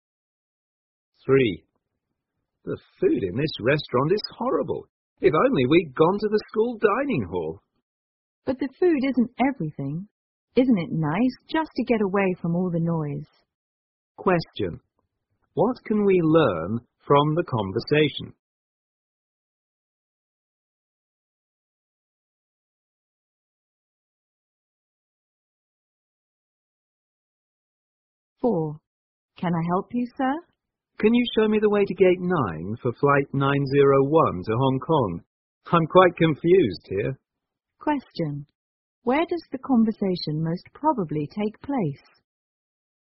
在线英语听力室090的听力文件下载,英语四级听力-短对话-在线英语听力室